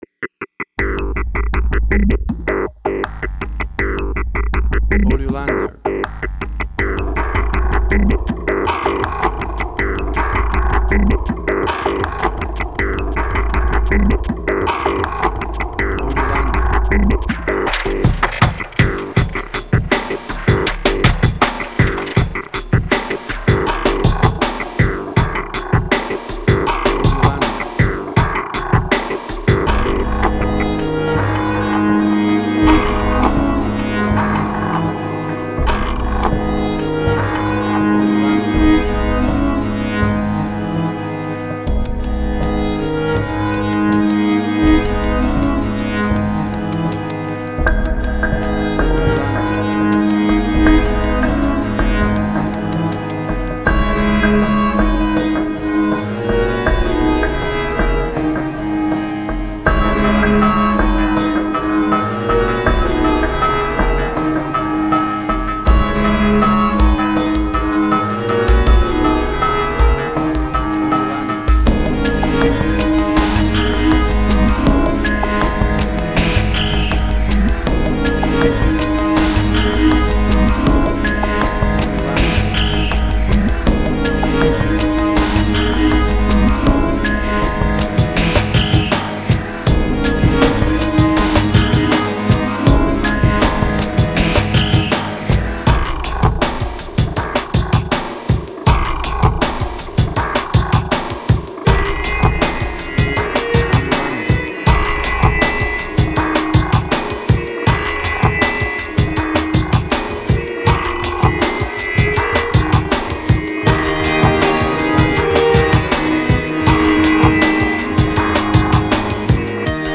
IDM, Glitch.
emotional music
WAV Sample Rate: 16-Bit stereo, 44.1 kHz
Tempo (BPM): 80